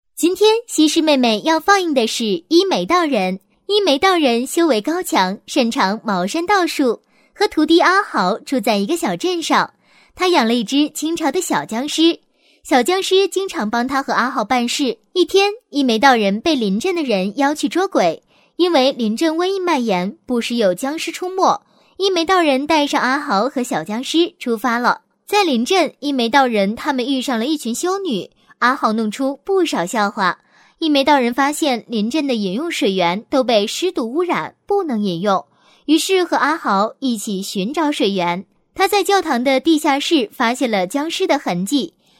女218-电影解说类《西施放送会》
女218-温柔甜美 素人自然